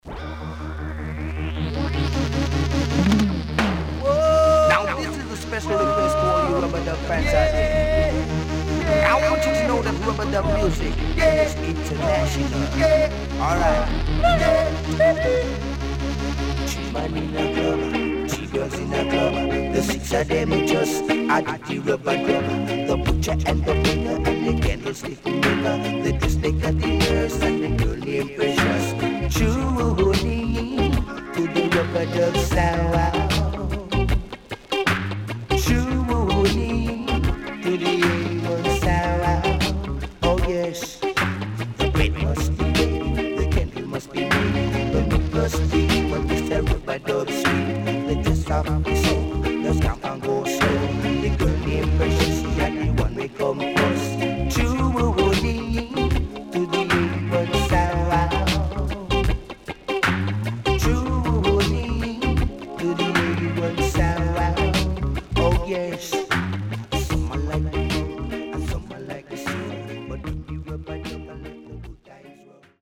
ダンスホール賛歌.やや曇りあり